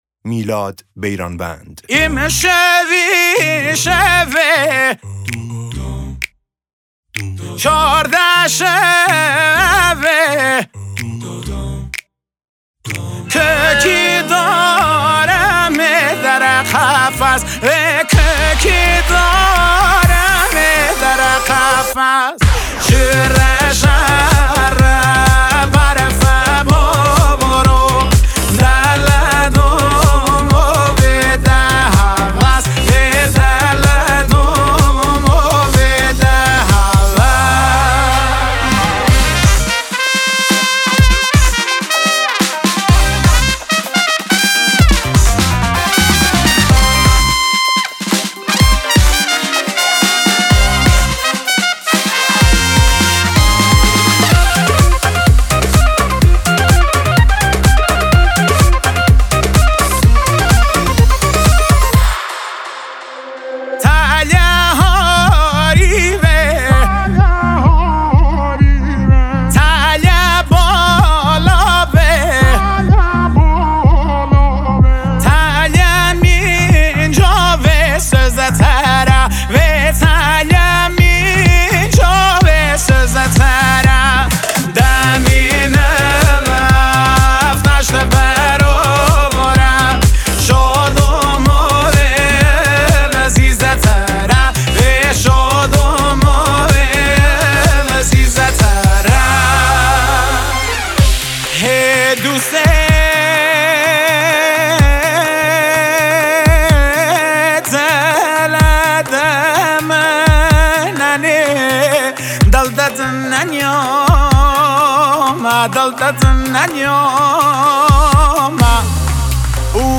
آهنگ لری